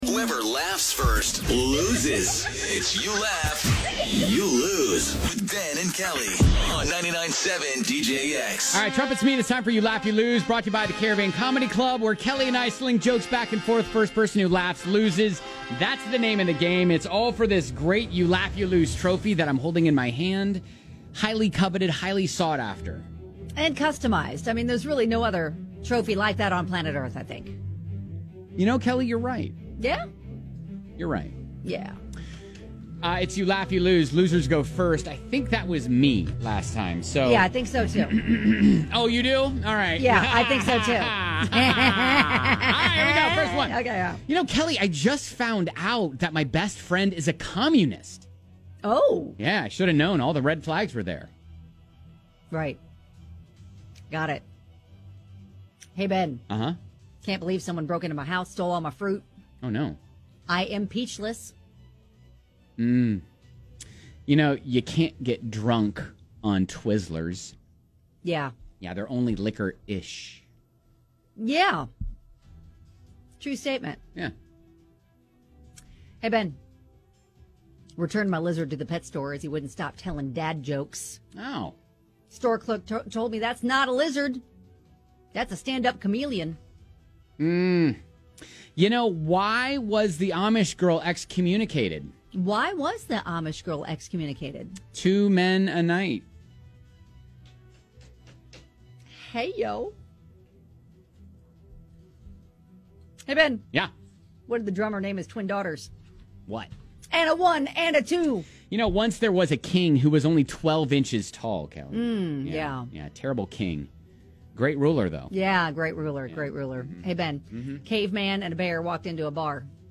toss jokes back and forth until someone laughs